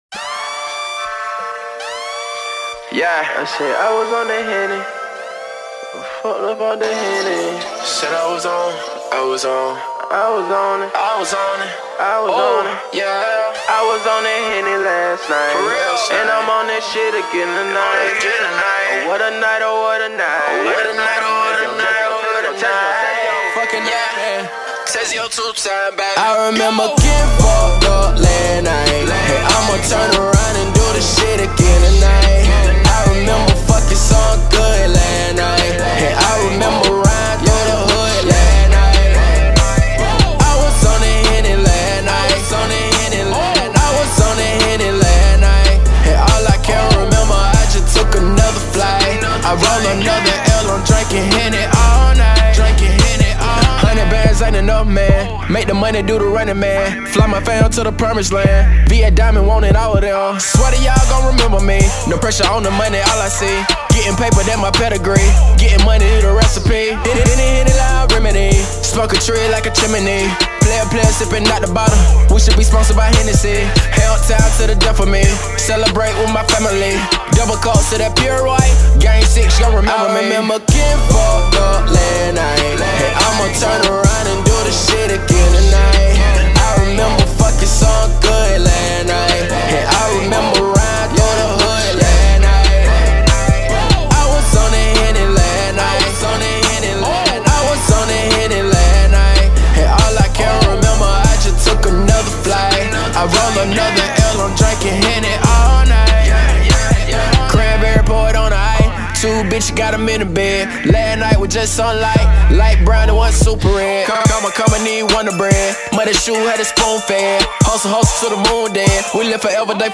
Hiphop
Description : SMOOTH RAP TRACK.